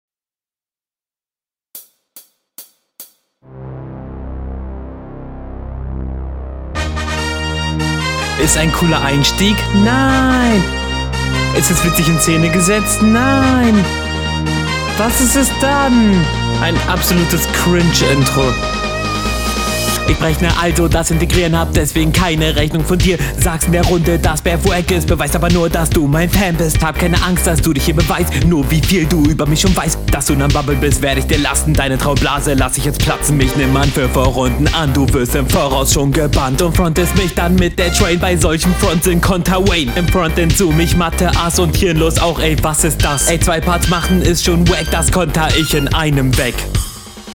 Flow: bist deinem Gegner deutlich überlegen, was will man auch auf so einem Beat machen.